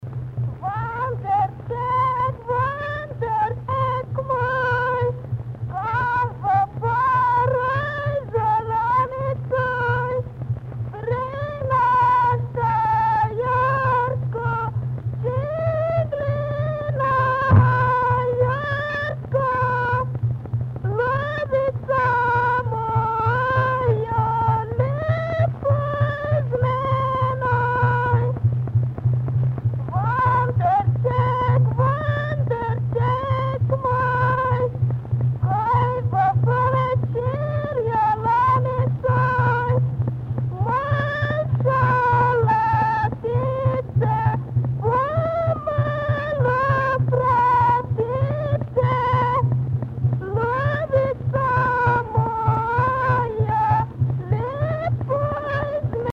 Dialect: A (Slovenian)
Locality: Tišina/Csendlak
Comment: This song is a seemingly light love song which shows a clear Central Slovenian influence, both on the lexical and the phonological level.